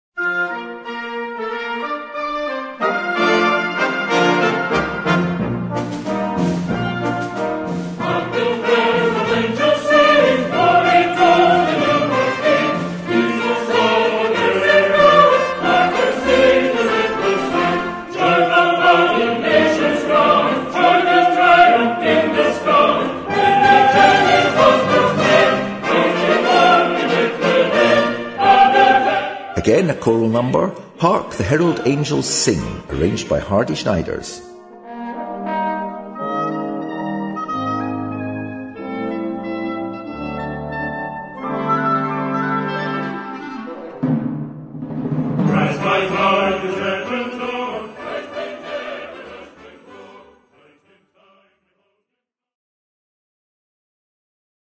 Incl. chorus SATB.